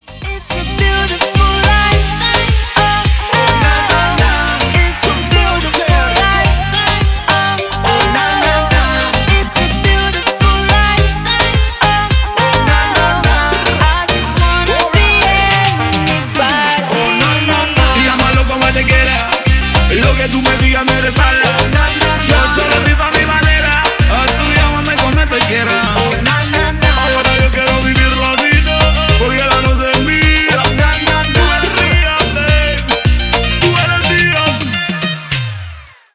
Поп Музыка
клубные # латинские